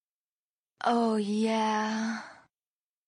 278灯泡音效14-59小钢炮
278灯泡音效14.mp3